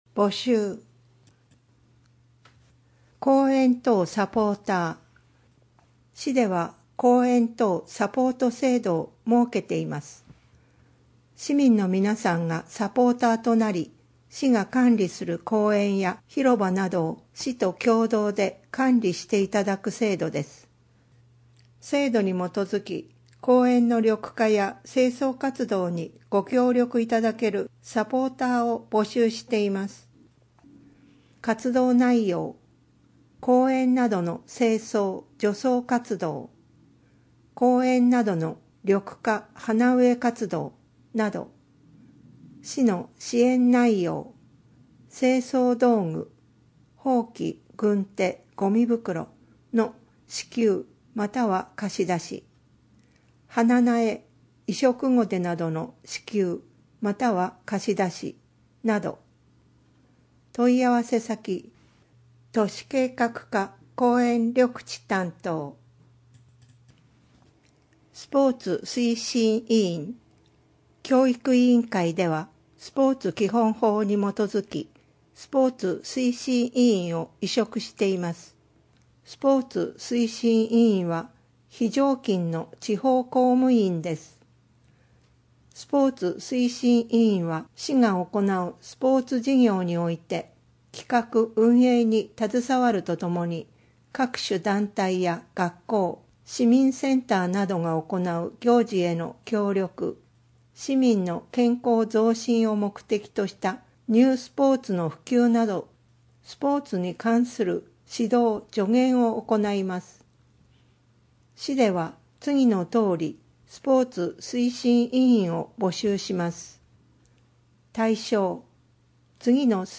声の広報つるがしまは、「鶴ヶ島音訳ボランティアサークルせせらぎ」の皆さんが「広報つるがしま」の内容を音訳し、「デイジー鶴ヶ島」の皆さんがデイジー版CDを製作して、目の不自由な方々へ配布をしています。